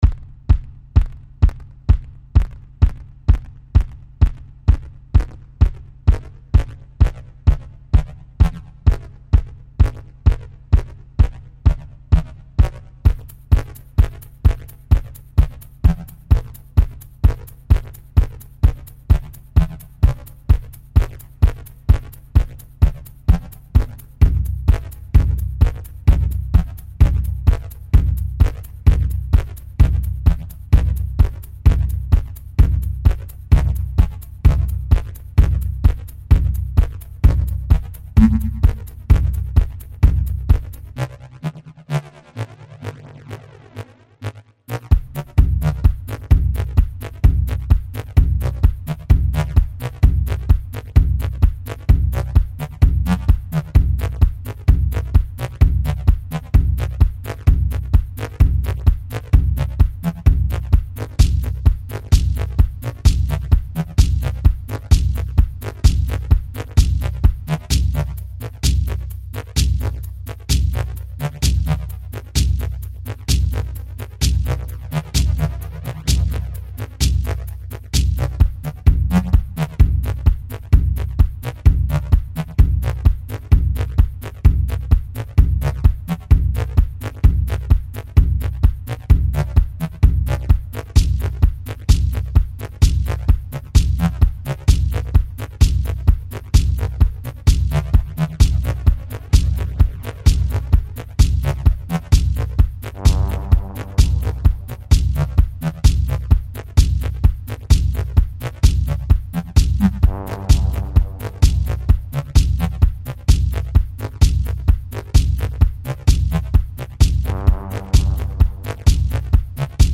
Musikstil: Techno